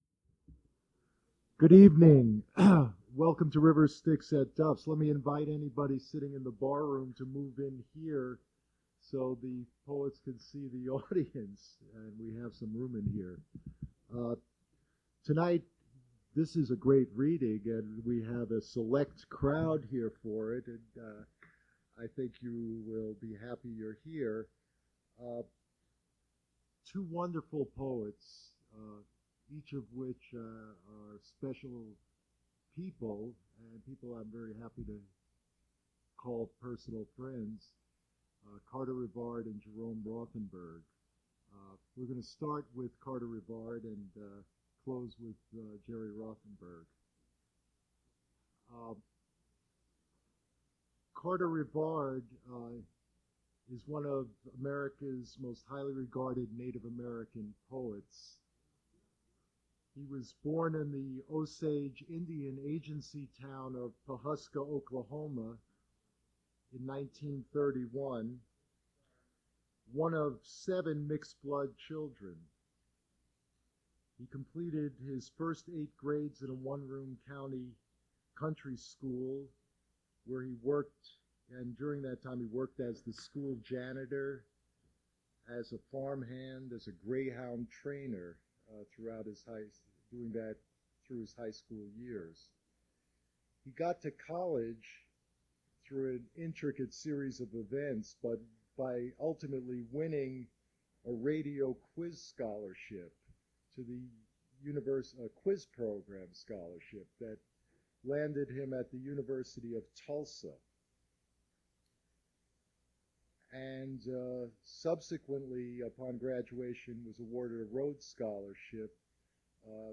Poetry reading featuring Carter Revard
Attributes Attribute Name Values Description Carter Revard poetry reading at Duff's Restaurant.
mp3 edited access file was created from unedited access file which was sourced from preservation WAV file that was generated from original audio cassette.
Gap in Audio at 47:13